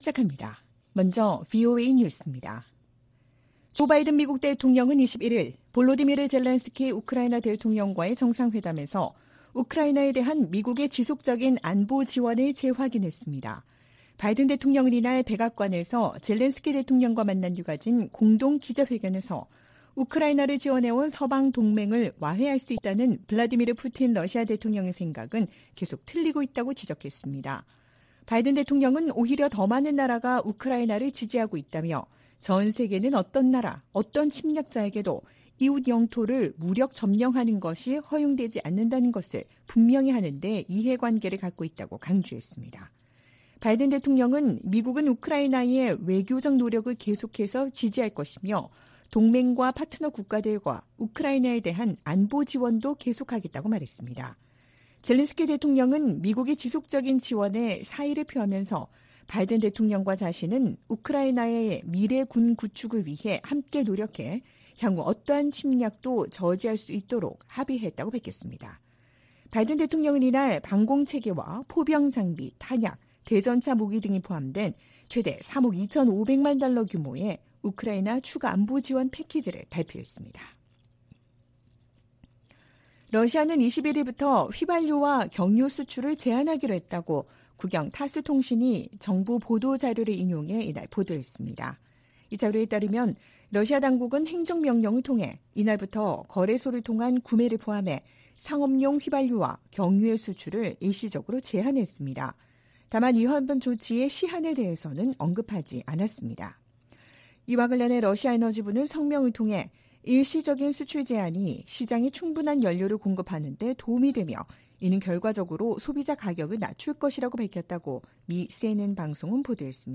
VOA 한국어 '출발 뉴스 쇼', 2023년 9월 23일 방송입니다. 북한이 우크라이나 전쟁에 쓰일 무기 등 관련 물자를 러시아에 지원할 경우 제재를 부과하도록 하는 법안이 미 하원에서 발의됐습니다. 한국 정부가 북-러 군사협력에 대해 강경 대응을 경고한 가운데 러시아 외무차관이 조만간 한국을 방문할 것으로 알려졌습니다. 발트 3국 중 하나인 리투아니아가 북-러 무기 거래 가능성에 중대한 우려를 나타냈습니다.